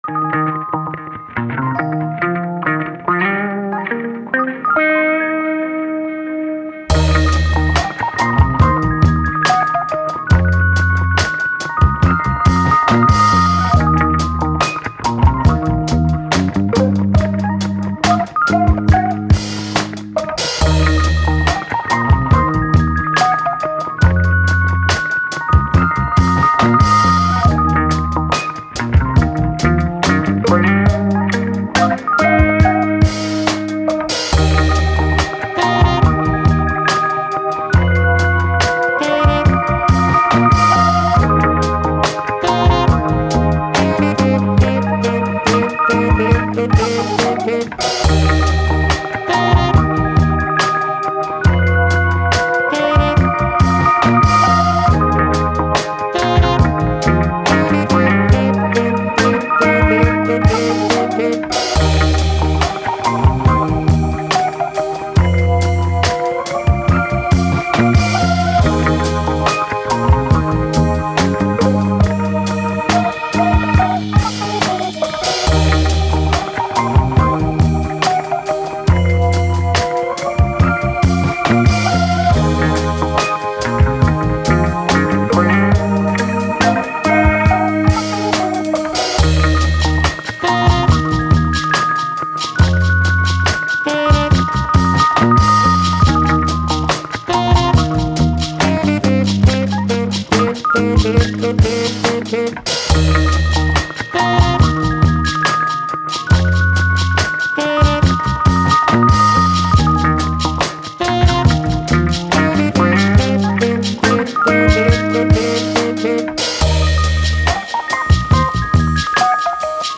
Soul, Vintage, Soul, Playful, Vibe, Positive